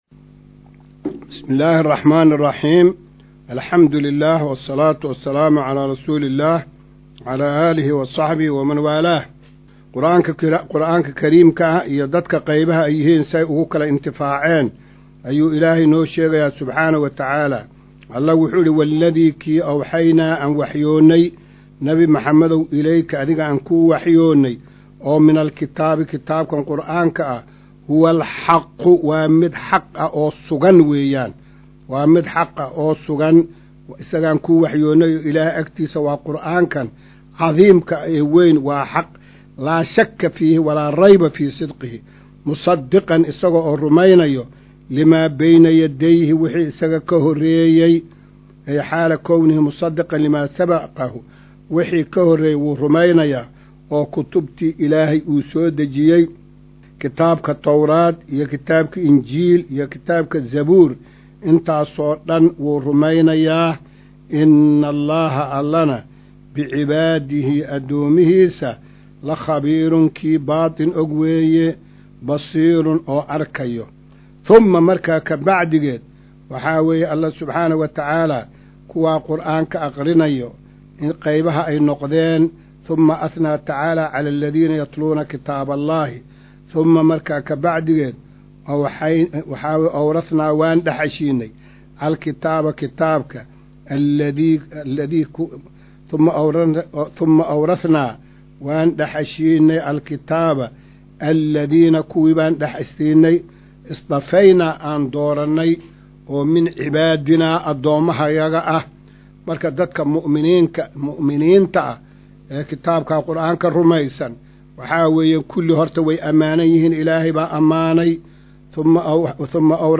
Maqal:- Casharka Tafsiirka Qur’aanka Idaacadda Himilo “Darsiga 207aad”